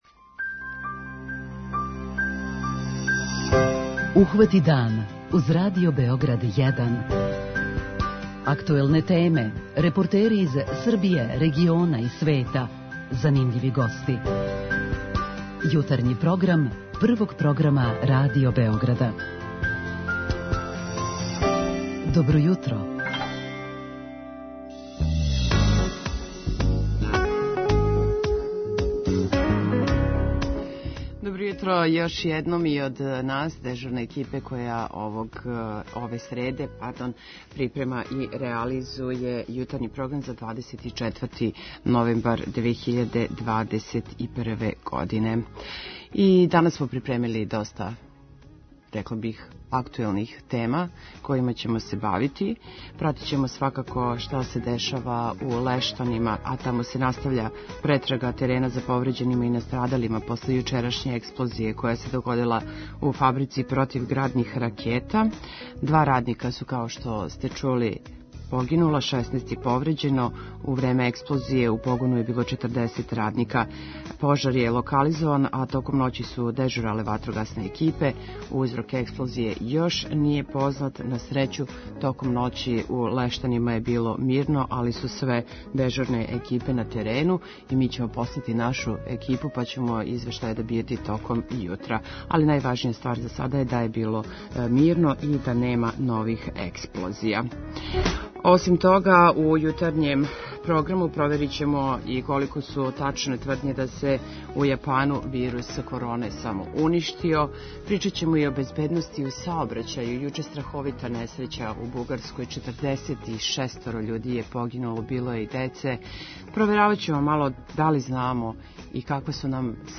преузми : 37.77 MB Ухвати дан Autor: Група аутора Јутарњи програм Радио Београда 1!